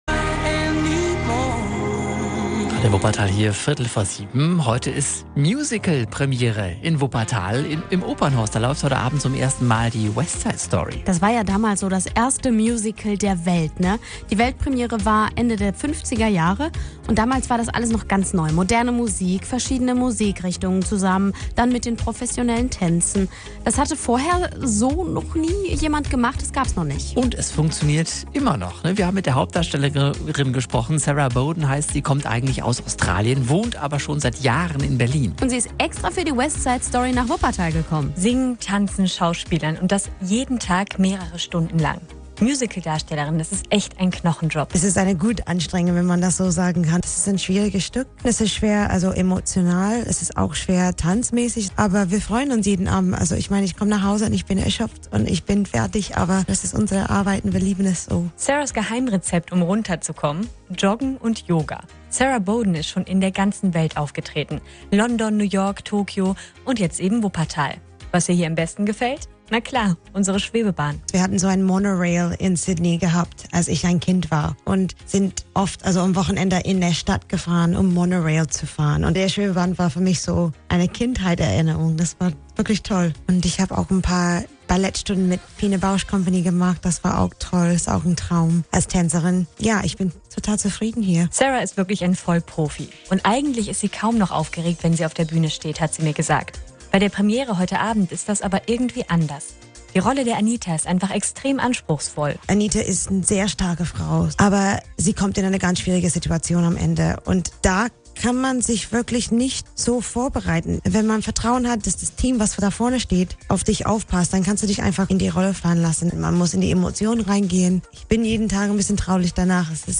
A little Radio interview i did here in Wuppertal!